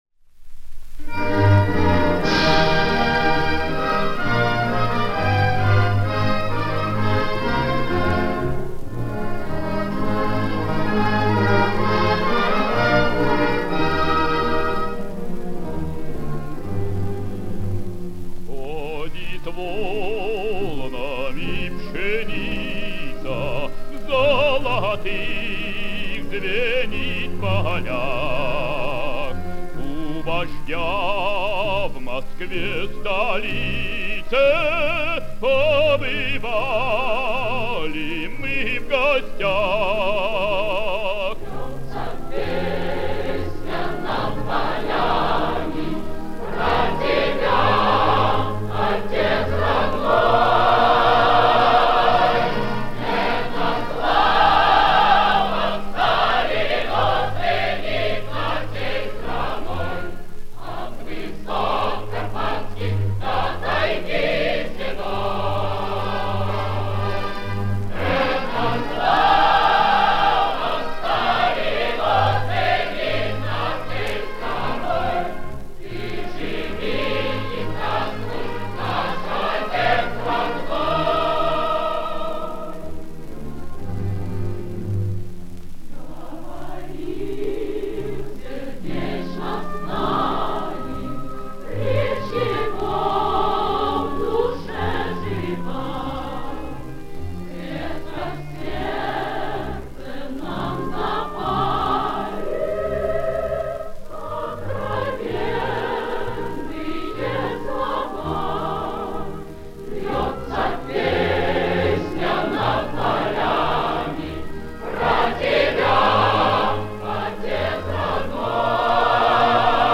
Повышение качества (на сайте запись перечищенная).